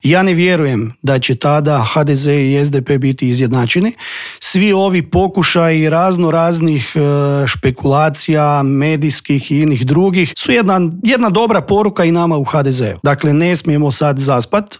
ZAGREB - Nakon što su predstavnici kineske kompanije China Shipbuilding Industry Corporation napustili Hrvatsku i dok kuhaju sudbonosnu odluku o Uljaniku i 3. maju, u Intervjuu tjedna Media servisa ministar gospodarstva Darko Horvat ekskluzivno je otkrio kojem su modelu Kinezi najskloniji, što ih je najviše zainteresiralo i kad očekuje odluku.